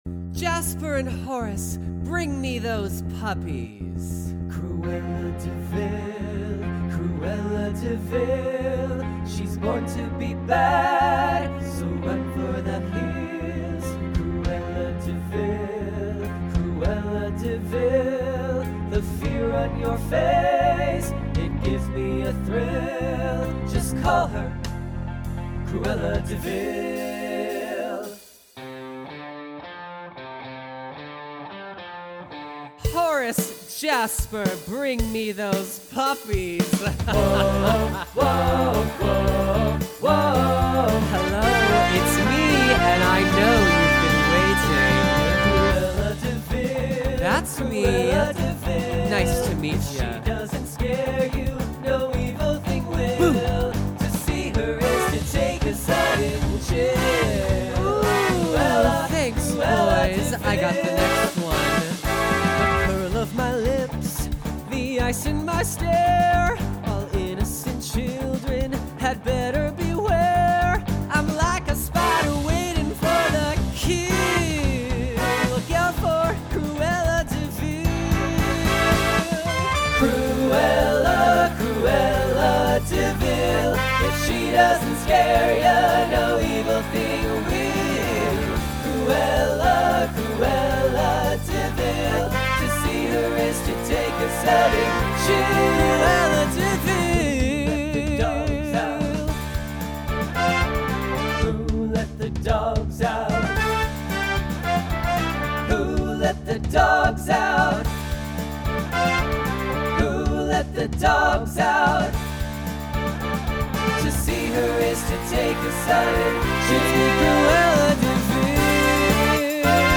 Genre Broadway/Film , Pop/Dance
Voicing TTB